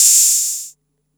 TR 808 Open Hat 01.wav